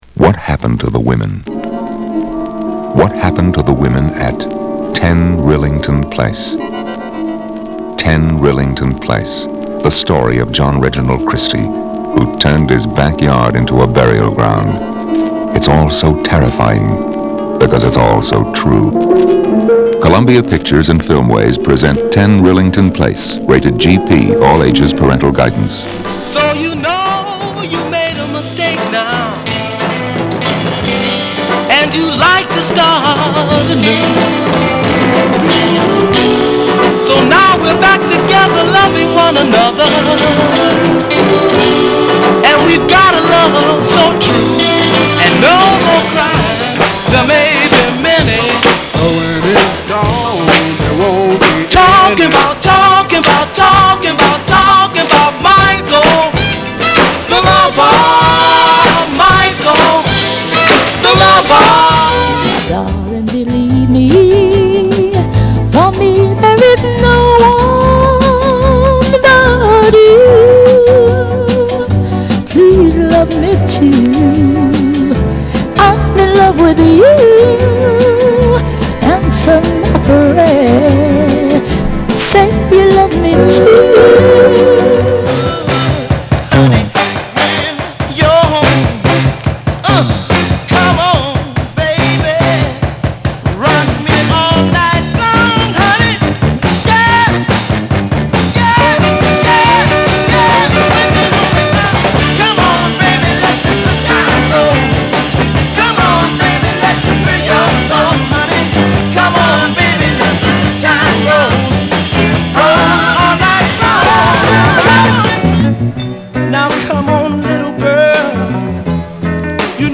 /盤質/両面やや傷あり/US PRESS